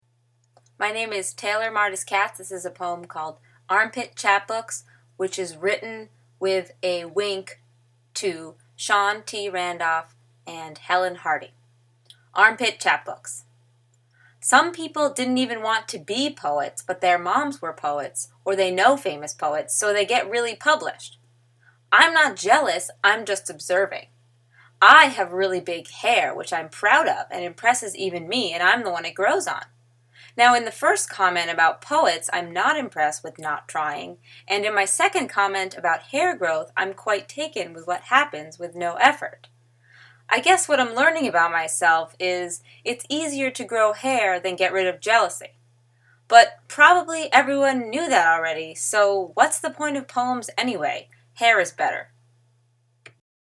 “Armpit Chapbooks” (poem out loud!).